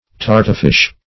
Search Result for " tartufish" : The Collaborative International Dictionary of English v.0.48: Tartuffish \Tar*tuff"ish\, Tartufish \Tar*tuf"ish\, a. Like a tartuffe; precise; hypocritical.